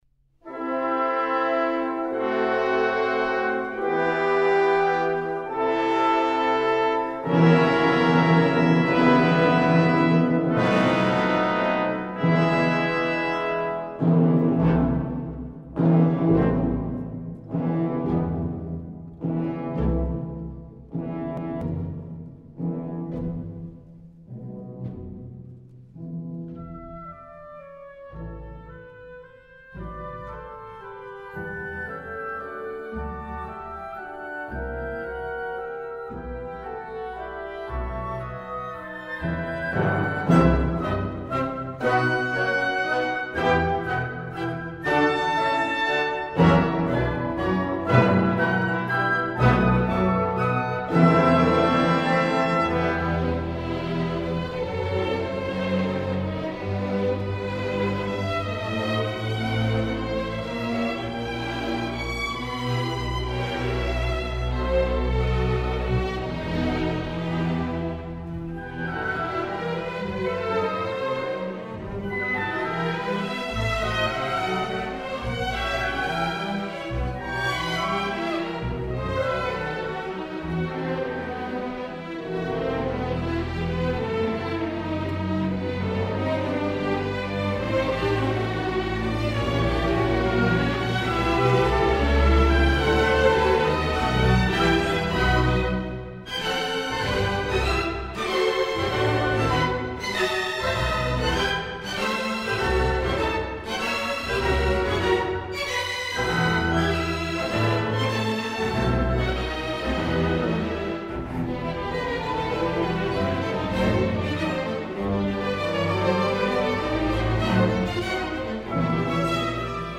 Brahms Symphonies. Tonhalle Orchestra Zurich. David Zinman, dir
Late Romantic.
These days the splendid David Zinman and the renowned Tonhalle Orchestra have released for the first time the complete Brahms symphonies recorded live at their homestead. And again this dream team infatuates the listener with a light, transparent rendition which avoids romantic gravity.
Tonhalle Orchestra; D. Zinman, dir